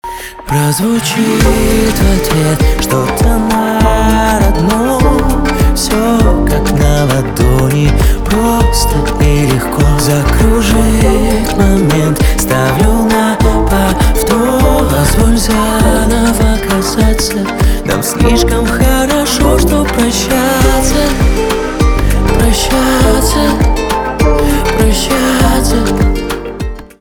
поп
грустные , гитара
печальные